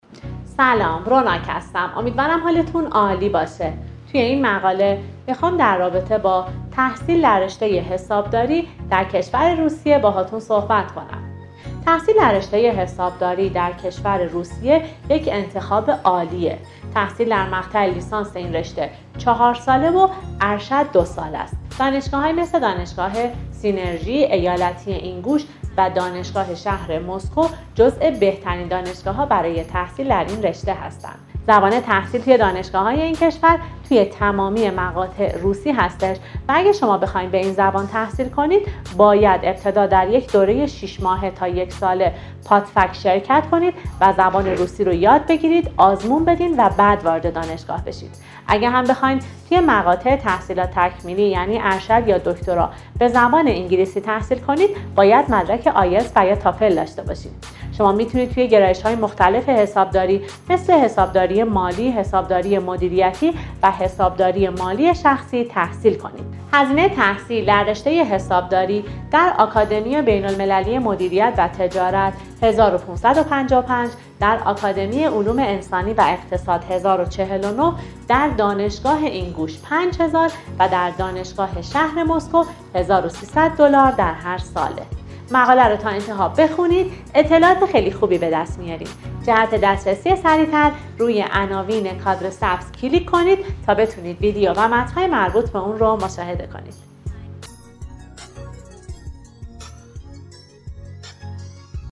صحبت های همکار ما را در این زمینه بشنوید و راهنمایی های بیشتر را از مشاورین ما دریافت کنید.